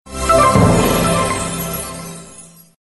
Прикольный рингтон смс для вашего телефона